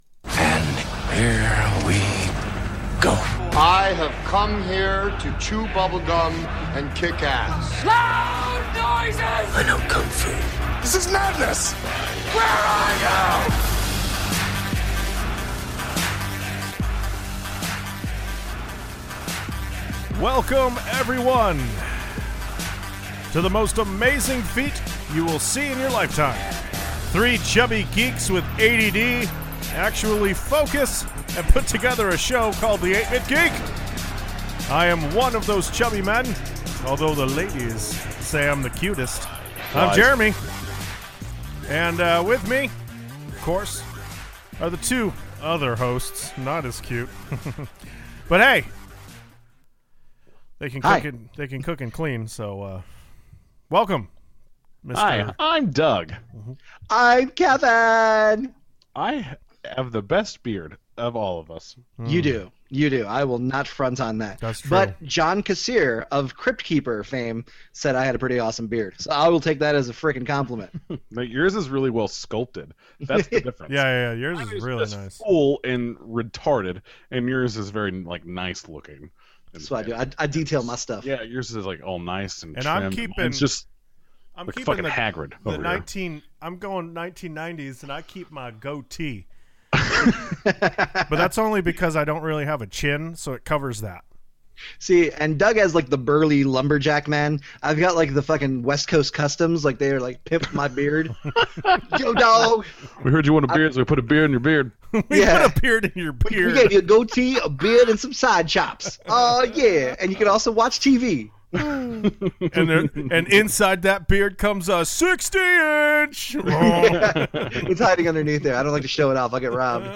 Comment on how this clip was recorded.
Google Hangouts did everything it could to ruin this week’s show, but we made it our biotch!